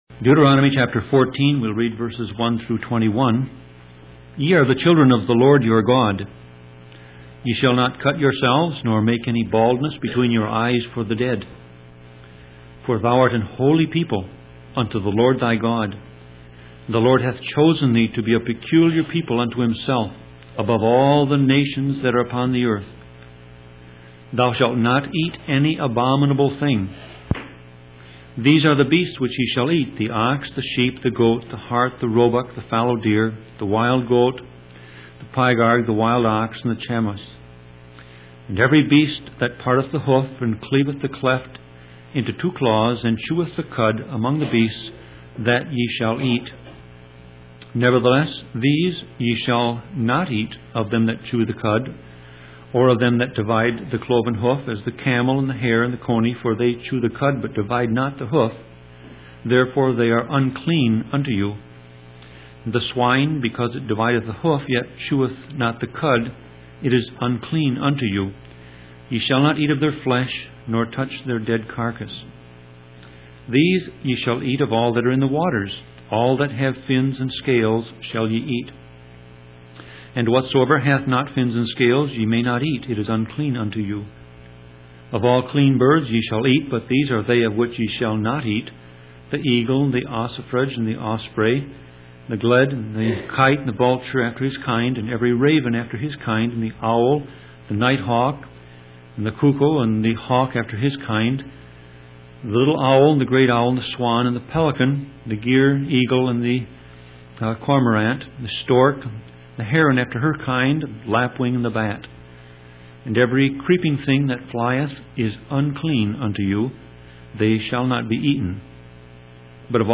Sermon Audio Passage: Deuteronomy 14:1-21 Service Type